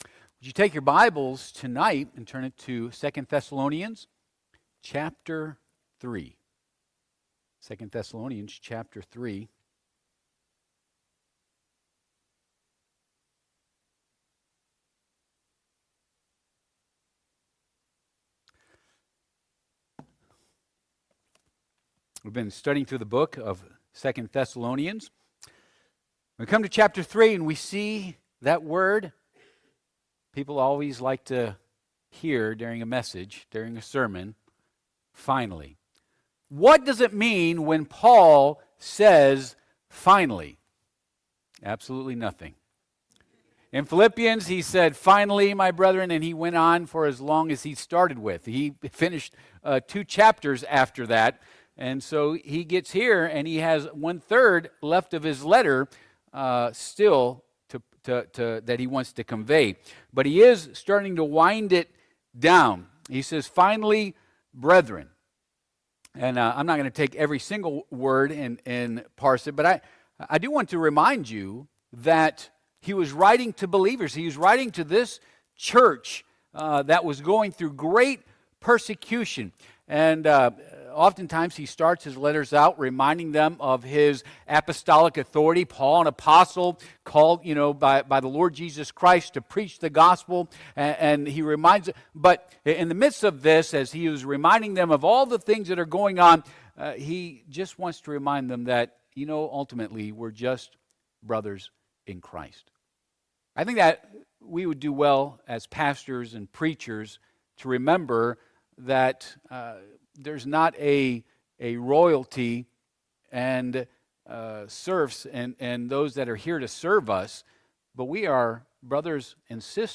2 Thess. 3:1-2 Service Type: Midweek Service « Is God Enough?